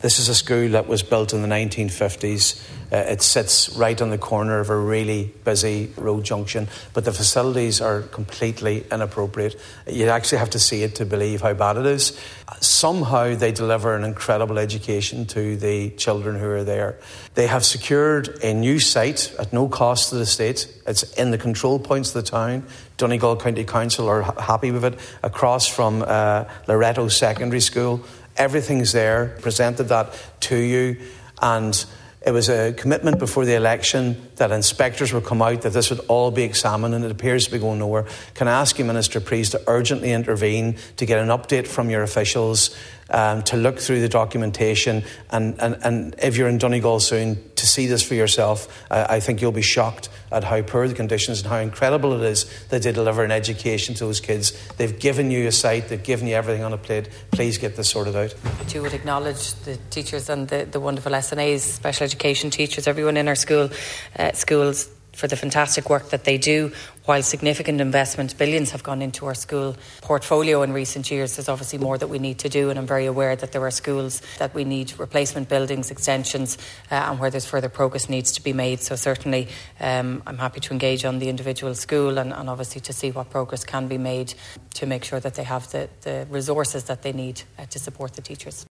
Minister Helen McEntee was responding to Donegal Deputy Padraig MacLochlainn in the Dail.